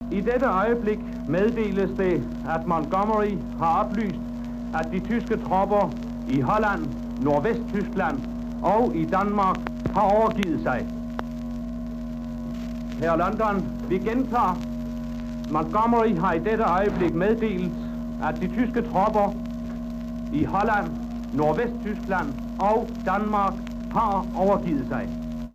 Sådan lød frihedsbudskabet fra London 4. maj 1945, da den danske radiospeaker Johannes G. Sørensen, via BBC's dansksprogede nyhedsudsendelse, kunne fortælle den danske befolkning, at de tyske tropper i Danmark havde overgivet sig.